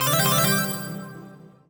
collect_item_jingle_05.wav